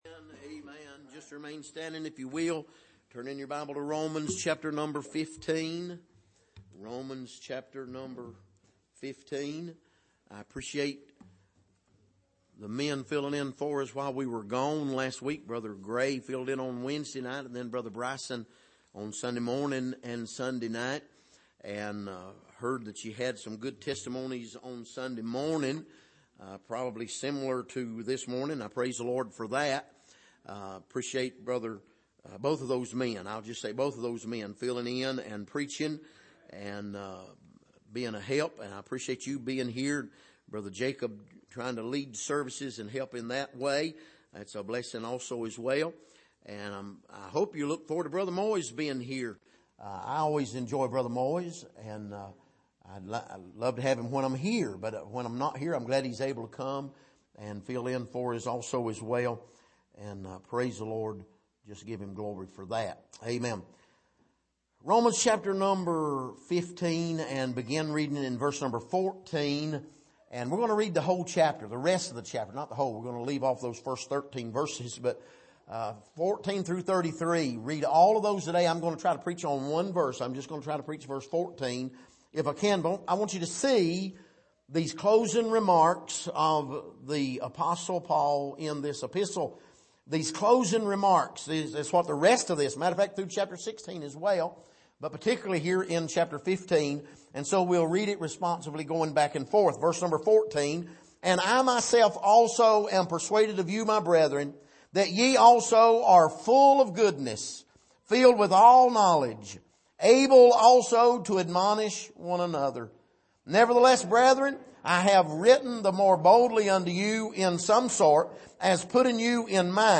Passage: Romans 15:14 Service: Sunday Morning